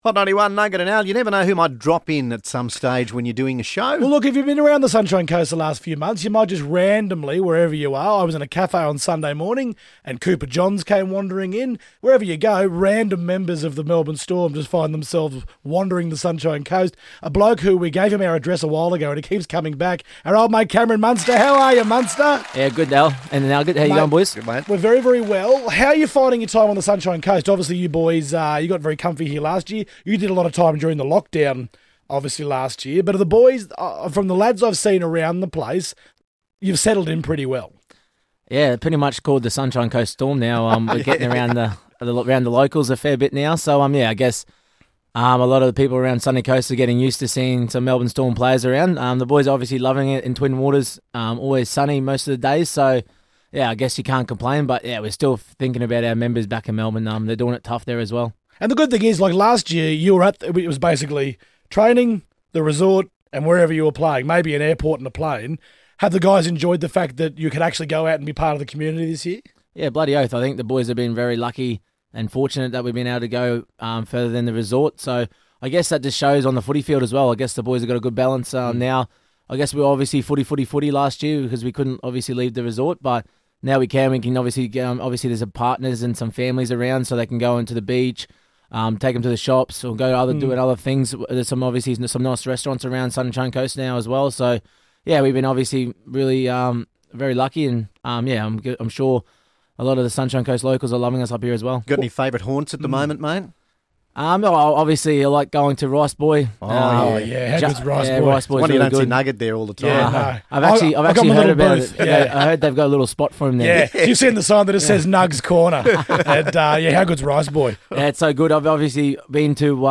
Cameron Munster dropped in to the studio today